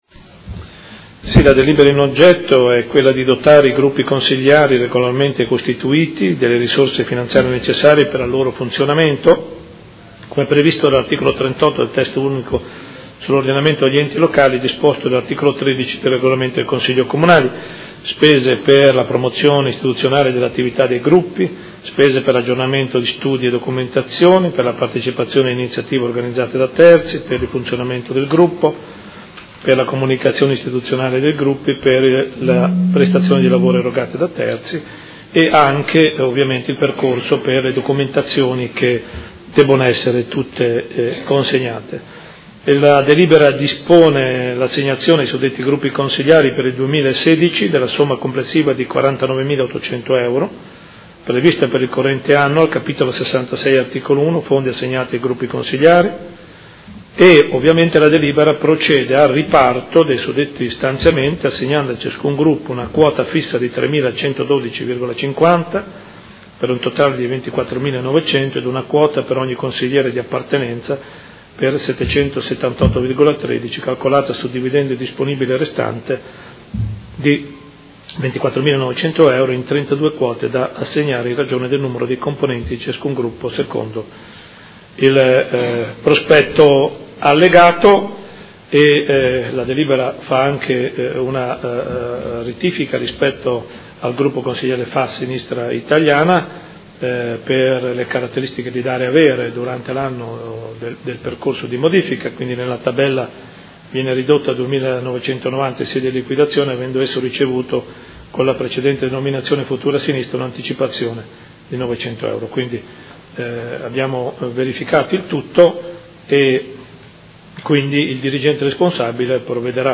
Seduta del 31/03/2016. Proposta di deliberazione: Risorse finanziarie ai Gruppi Consiliari – Mandato amministrativo 2014-2019 Anno 2016.